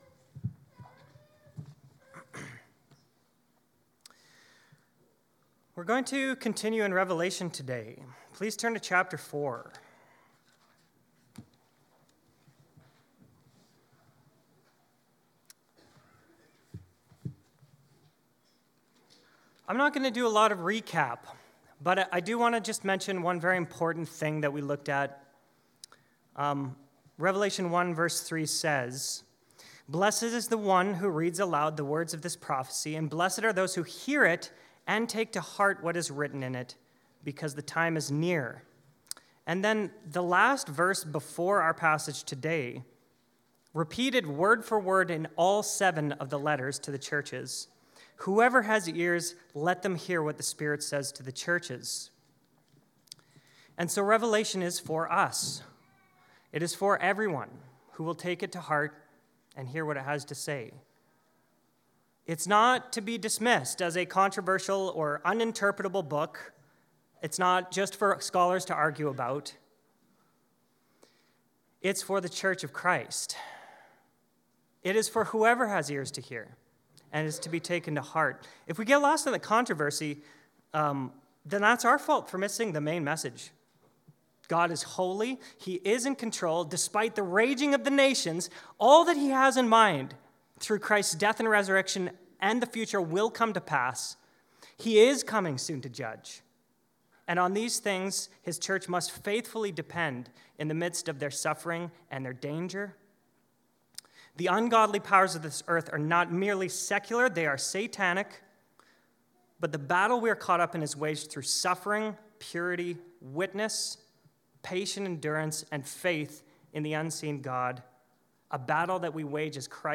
Sermons 2024 | Sermons | Webster Community Church